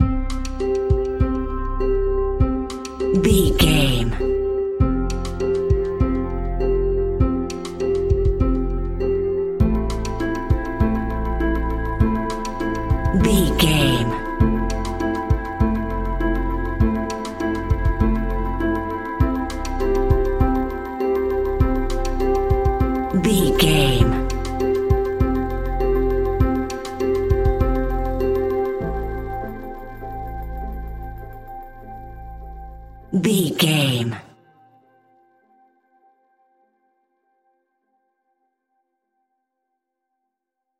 Ionian/Major
ominous
dark
suspense
eerie
synthesizer
drum machine
horror music